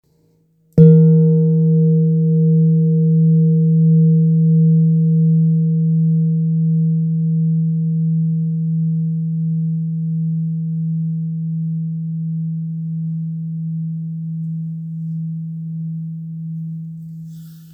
Kopre Singing Bowl, Buddhist Hand Beaten, Antique Finishing, Select Accessories
Material Seven Bronze Metal
It is accessible both in high tone and low tone .
In any case, it is likewise famous for enduring sounds.